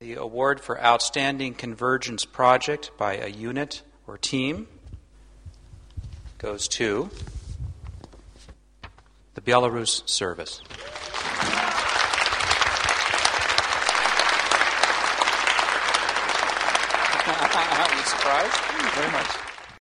2007. Уручэньне прэмі за мультымэдыйнасьць
Уручэньне ўзнагароды